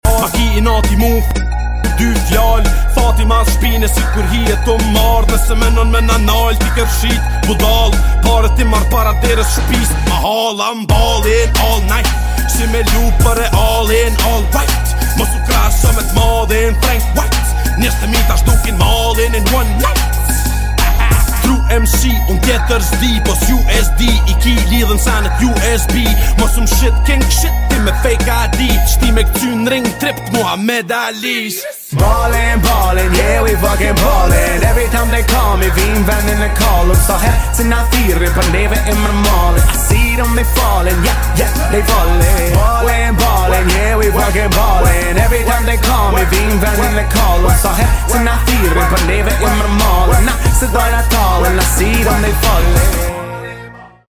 car service sergon BMW F36 sound effects free download
Rezultati? Pamje e fuqishme, tingull i moderuar dhe funksionalitet i plotë në çdo aspekt